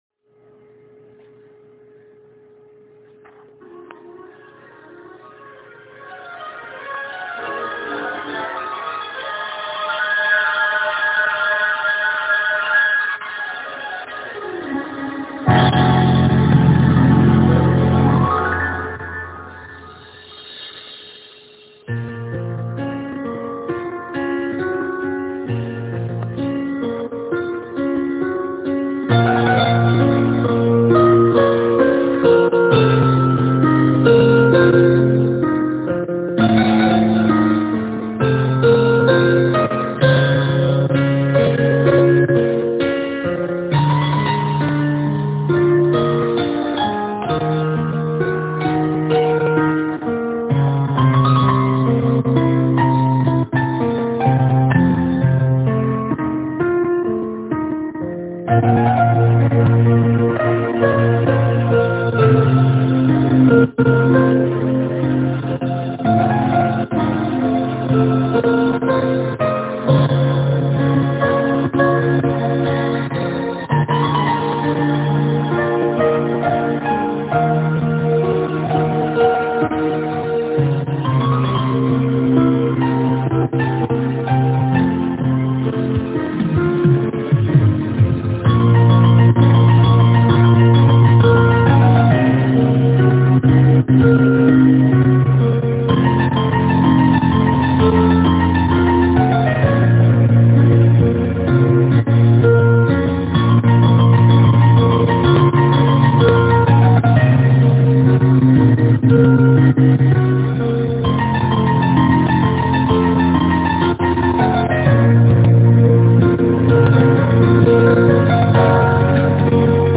Запись на телефон.